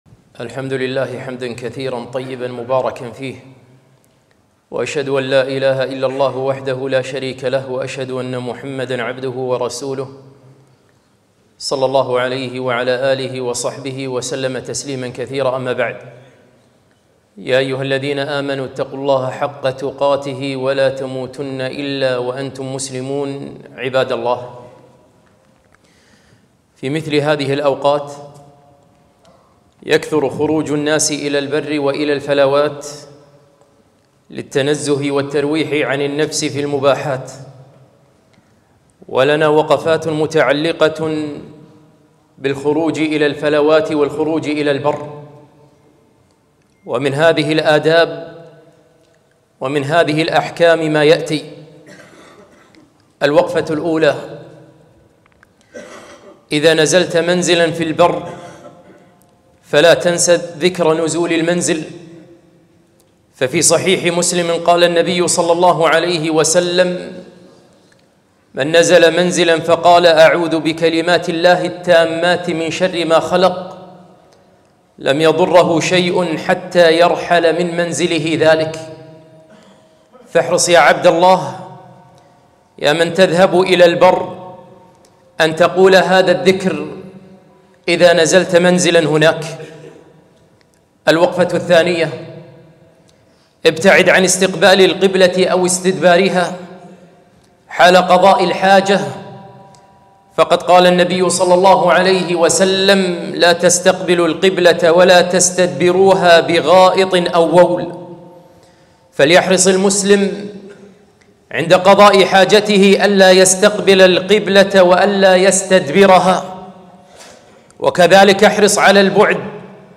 خطبة - مسائل متعلقة بالخروج إلى البر